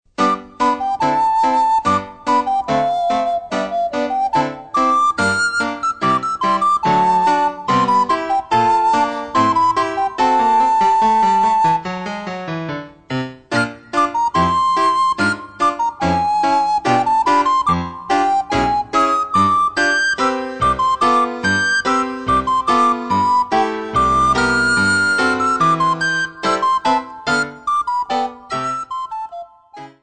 für Sopranblockflöte und Klavier
Besetzung: Sopranblockflöte und Klavier
Klassisches Blockflötenrepertoire auf Noten mit Playback-CD.